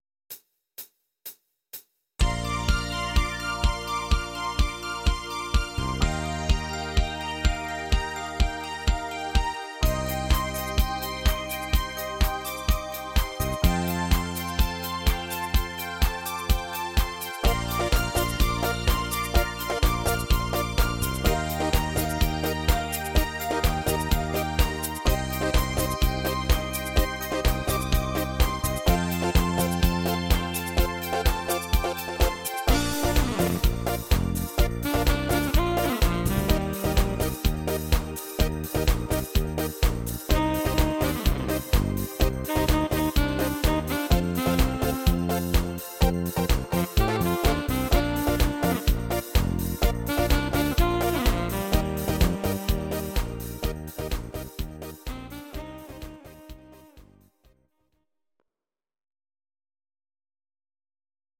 Discofox on Sax